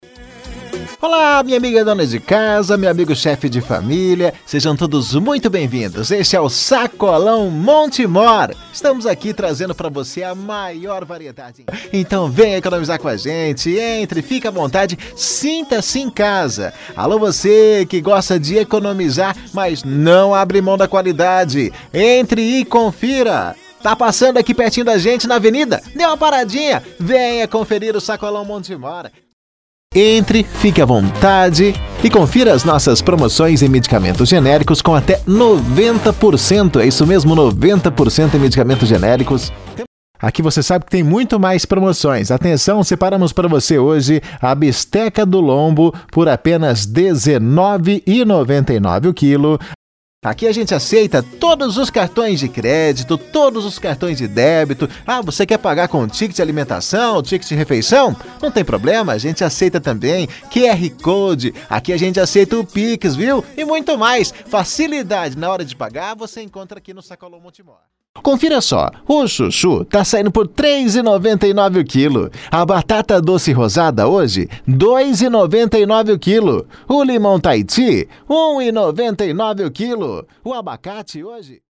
PORTA DE LOJA